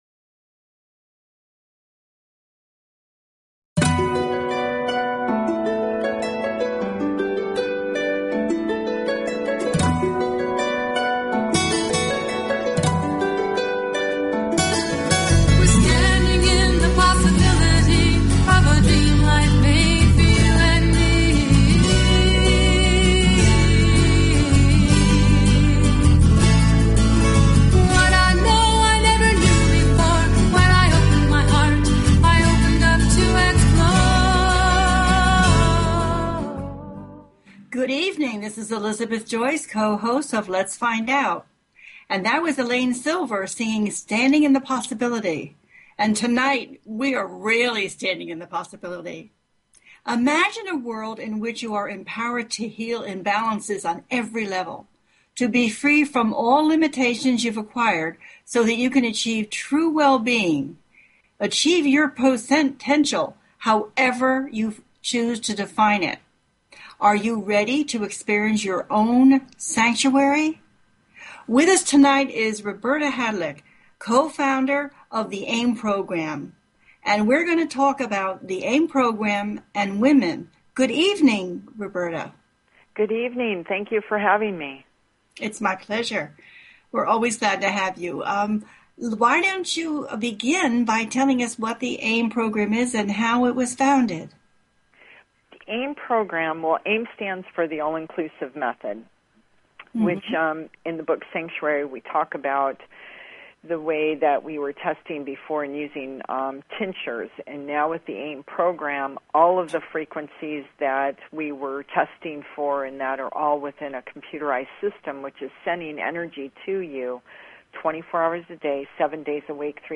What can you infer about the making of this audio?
The listener can call in to ask a question on the air.